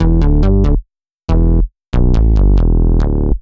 Index of /musicradar/french-house-chillout-samples/140bpm/Instruments
FHC_MunchBass_140-E.wav